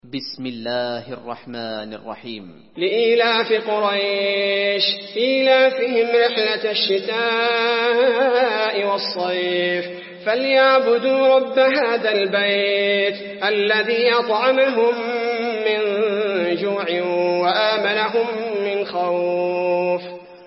المكان: المسجد النبوي قريش